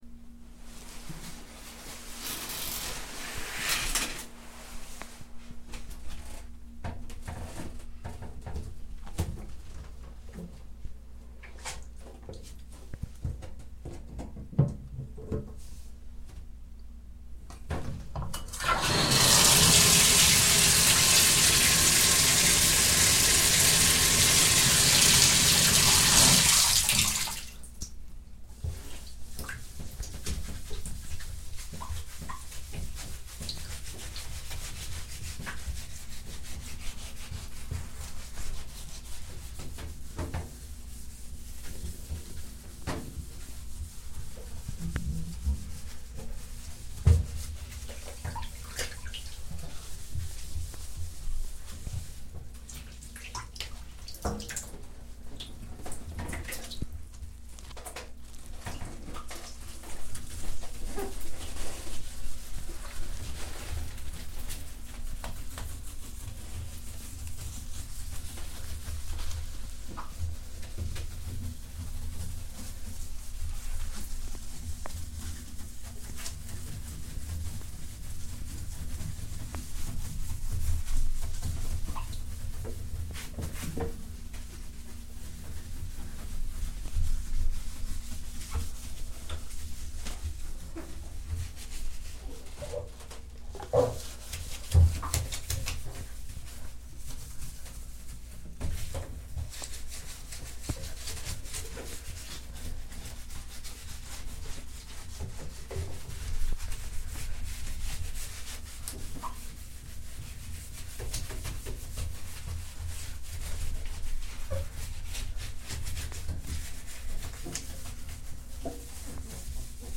Day11, Binaural Recording So Clean and Real It'll Have You Reaching For a Towel To Dry Off, headphones required